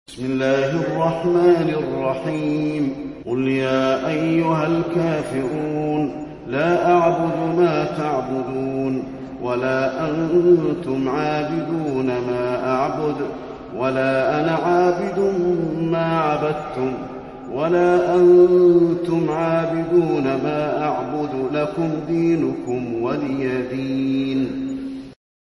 المكان: المسجد النبوي الكافرون